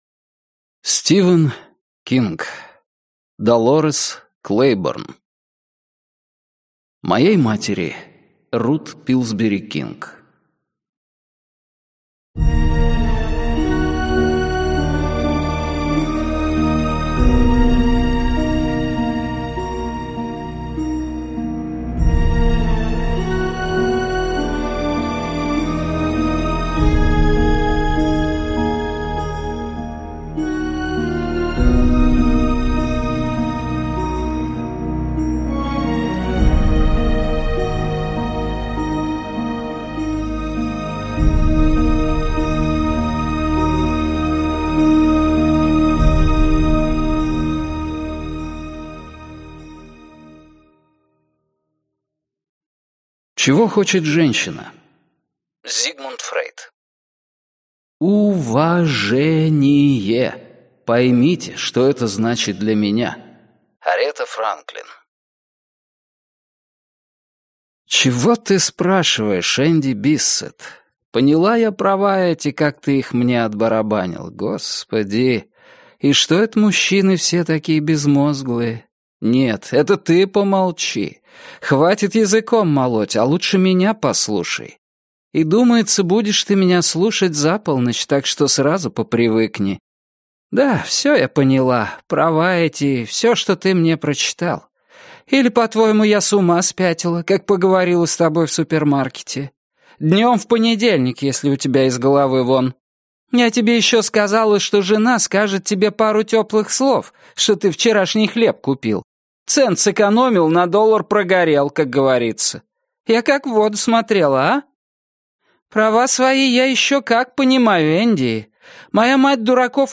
Аудиокнига Долорес Клейборн - купить, скачать и слушать онлайн | КнигоПоиск